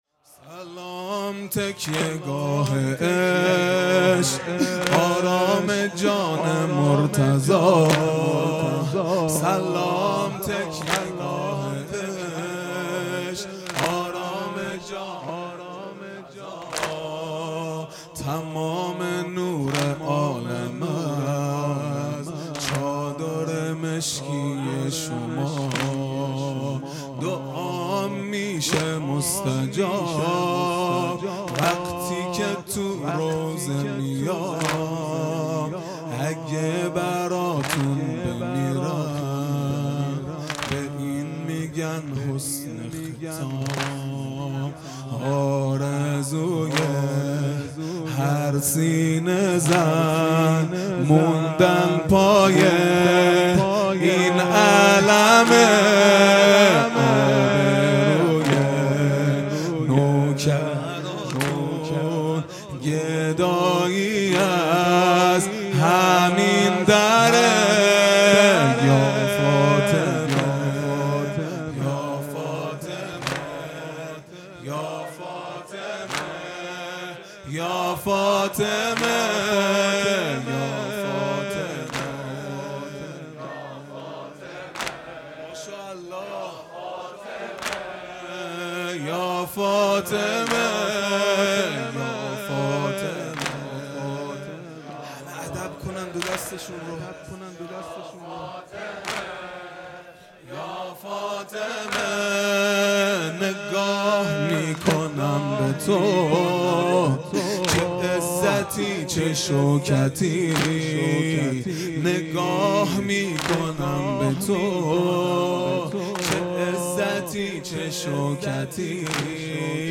خیمه گاه - هیئت بچه های فاطمه (س) - واحد | سلام تکیه گاه عشق | 26 آذر 1400